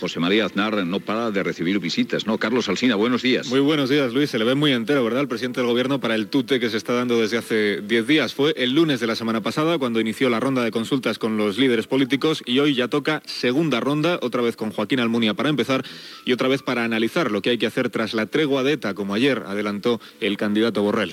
Luis del Olmo dona pas a Carlos Alsina per informar de les visites que rep el president del govern espanyol José María Aznar
Info-entreteniment
FM